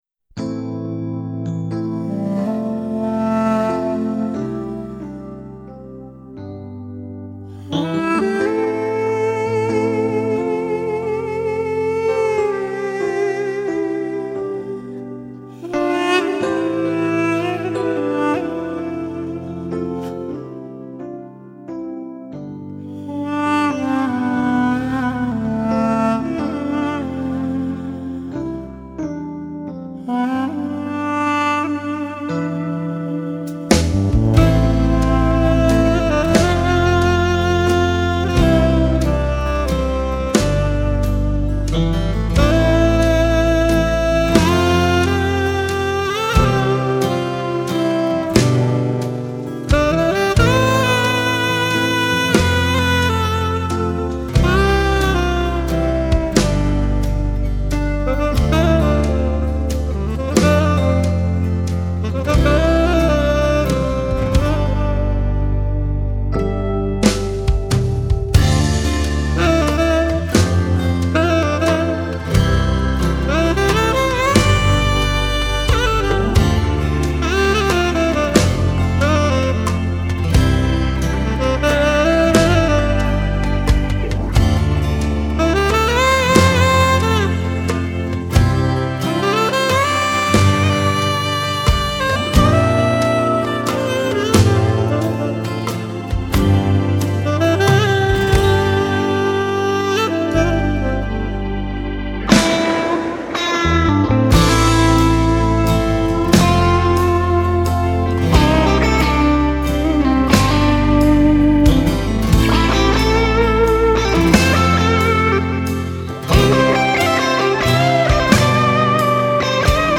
钢琴、竖琴、大提琴、手风琴、爱尔兰笛